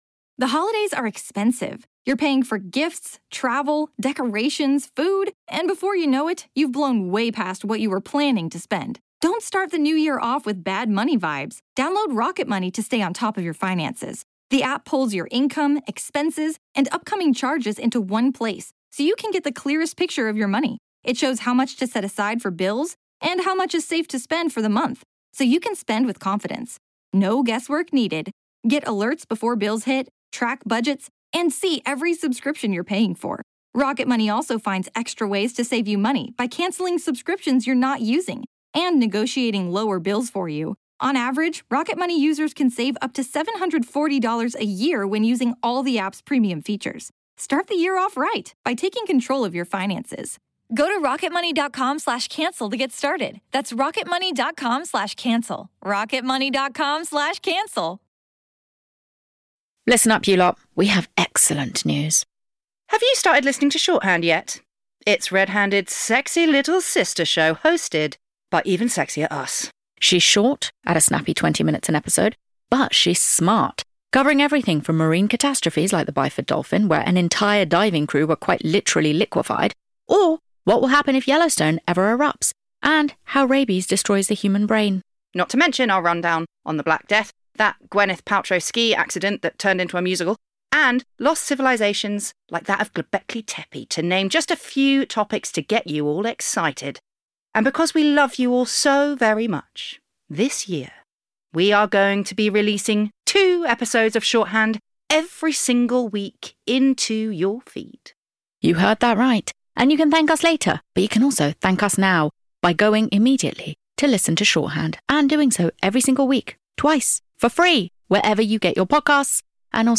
This powerhouse of super-sleuths leaves no stone unturned in solving a true crime from history, whilst imbibing their favorite adult beverages and inviting us to play along too. We also get to hear some personal true crime tales of woe from hosts and listeners alike and whilst the gang may not be able to solve everything, they sure as hell don't make it worse.